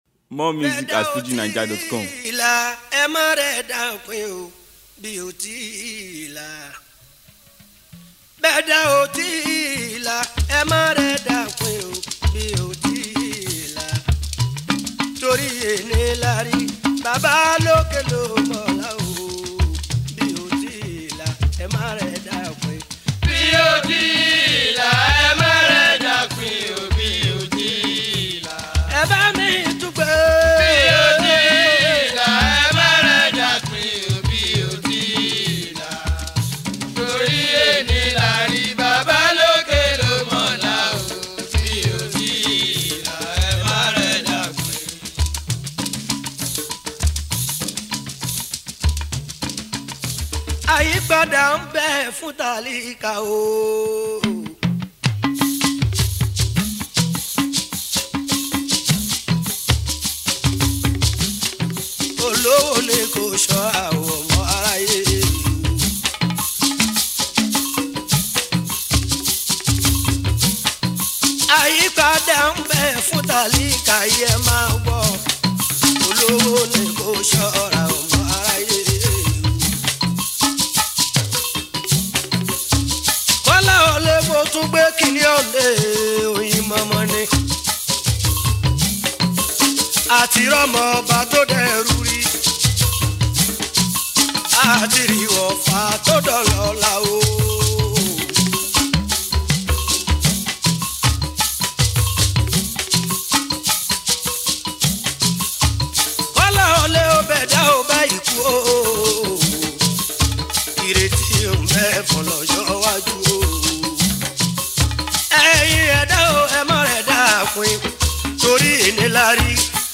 Legendary Yoruba fuji singer
old school track
Fuji